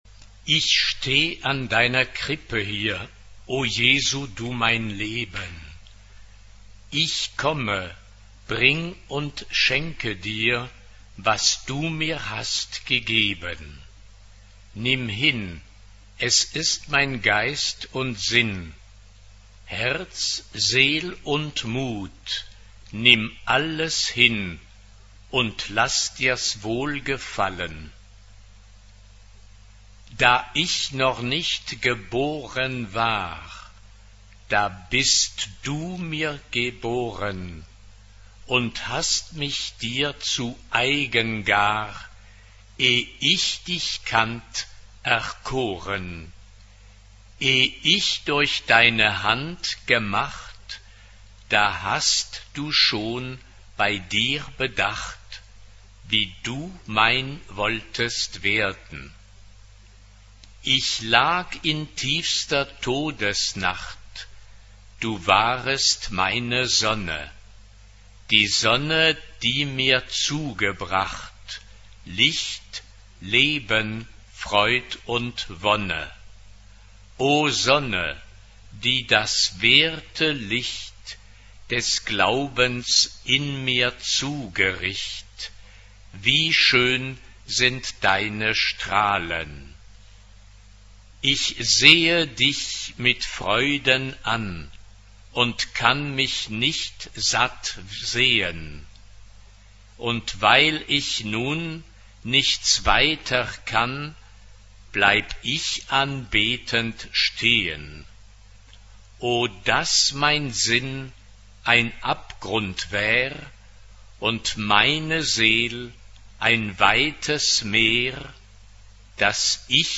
SSA (3 voices women) ; Full score.
Sacred ; Baroque Type of Choir: SSA (3 women voices )
Tonality: C minor